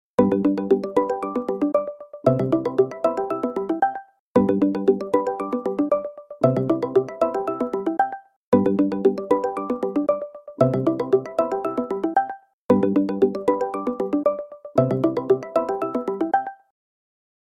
ringtones-google-duo.mp3